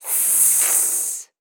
snake_hiss_01.wav